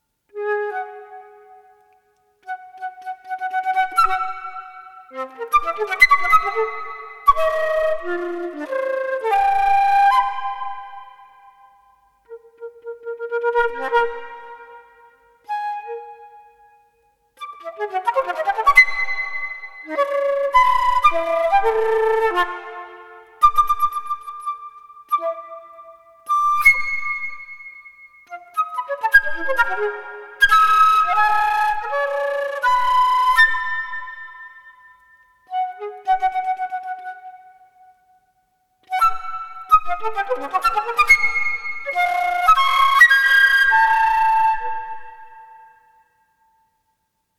Камерно-инструментальная музыка